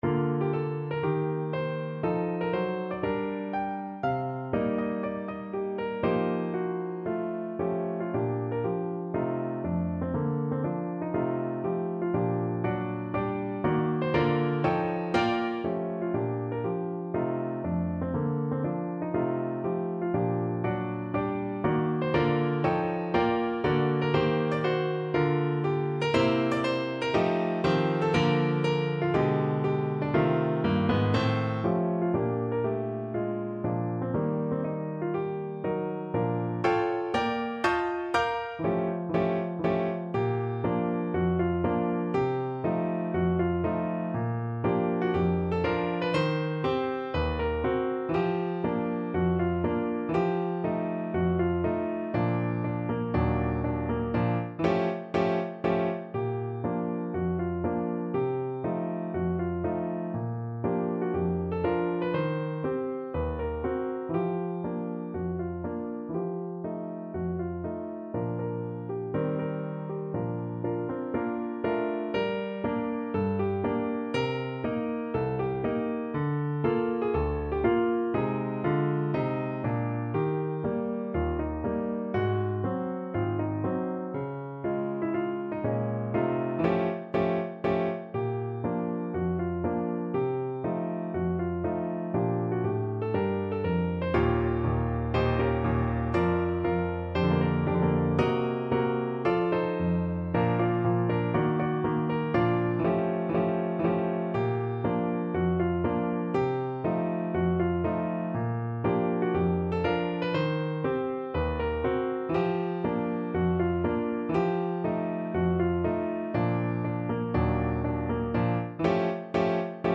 4/4 (View more 4/4 Music)
~ = 120 Moderato